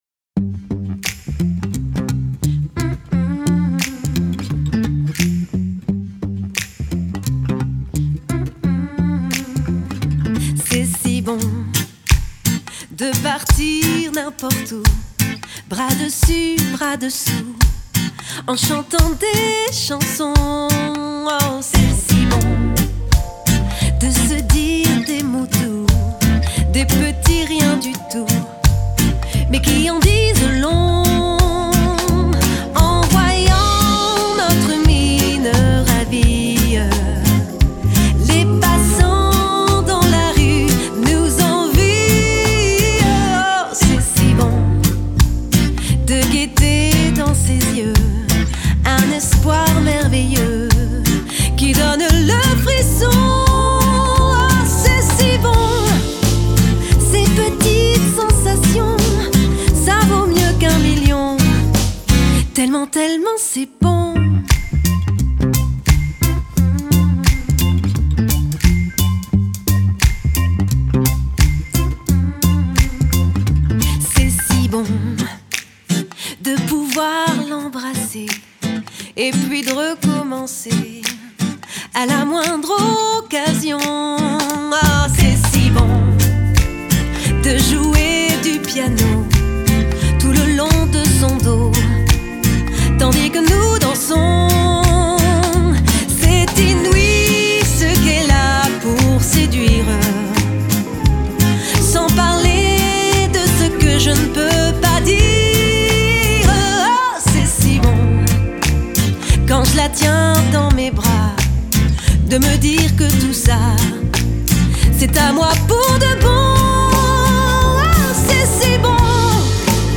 Chant
Guitare, Batterie
Basse, Clavier